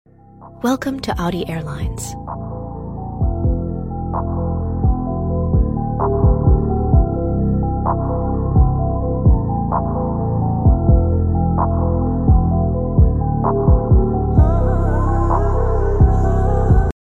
Raw Start Up Sound 🔥🙃 Sound Effects Free Download